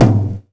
1ST-TOM04 -R.wav